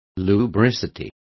Complete with pronunciation of the translation of lubricity.